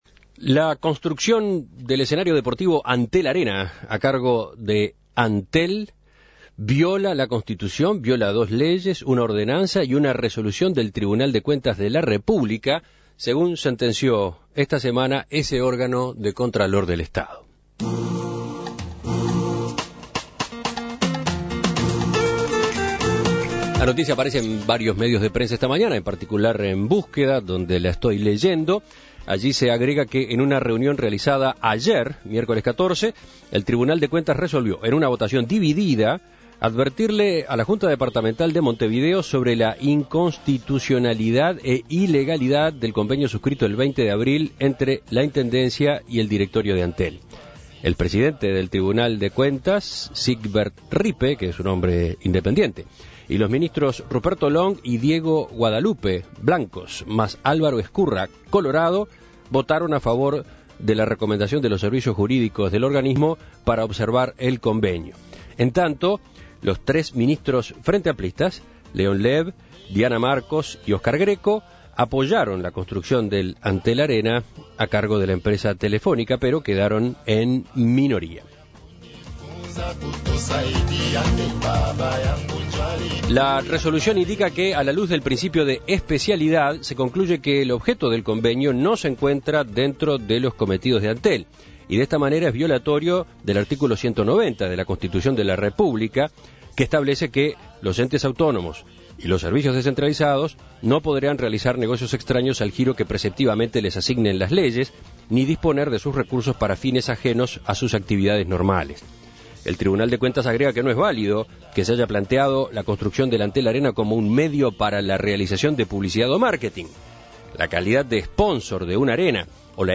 Escuche la entrevista a Carolina Cosse